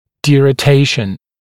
[dɪrə’teɪʃ(ə)n][дирэ’тэйш(э)н]деротация